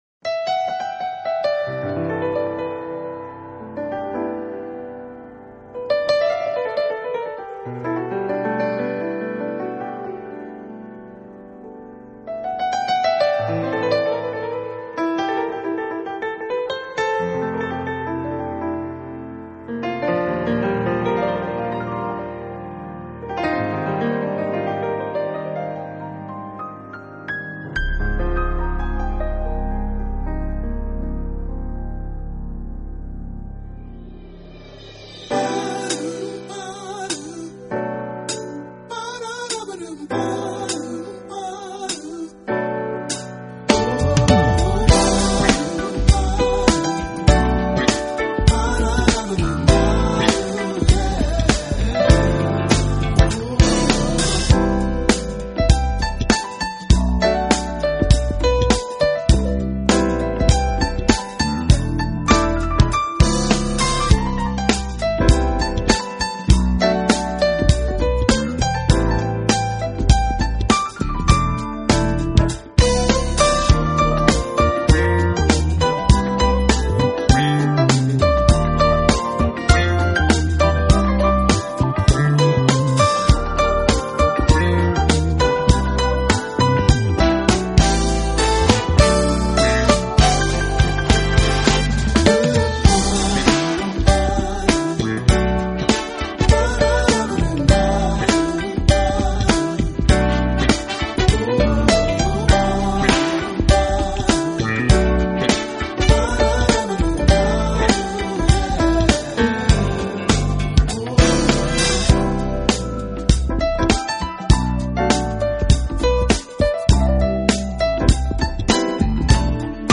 爵士钢琴
Genre: Jazz-Funk, Smooth Jazz